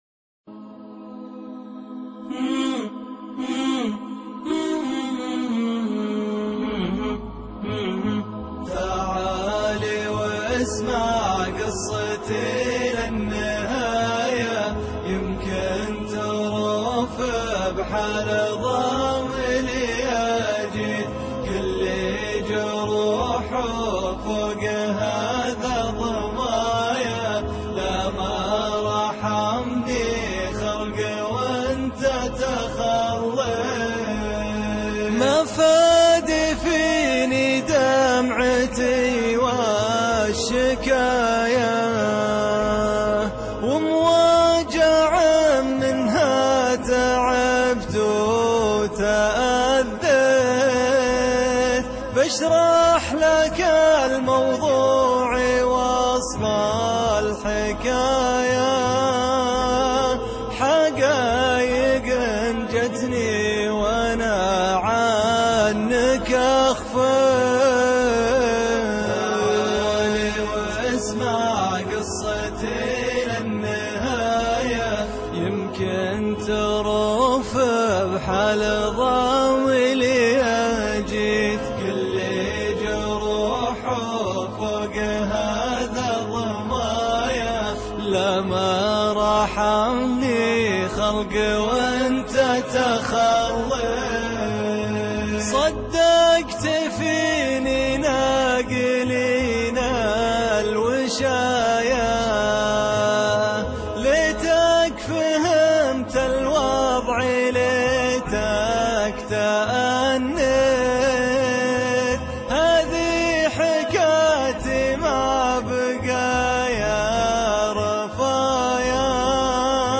شيلة حزينة